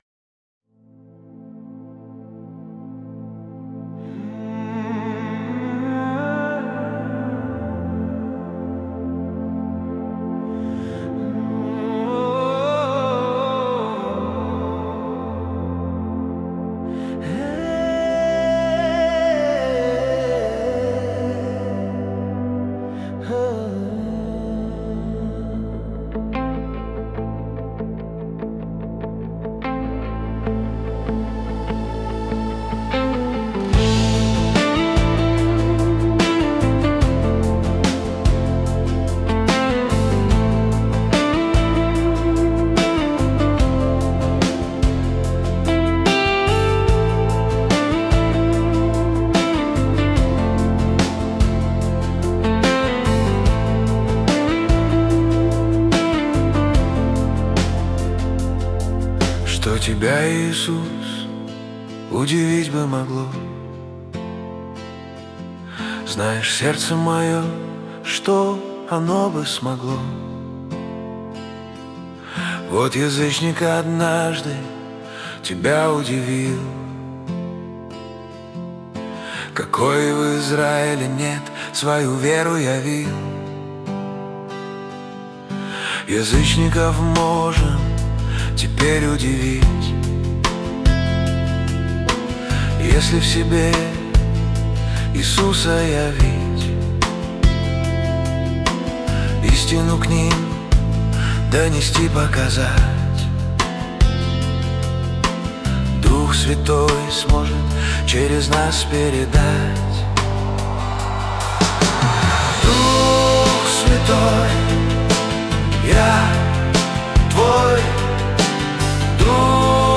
песня ai
141 просмотр 484 прослушивания 67 скачиваний BPM: 74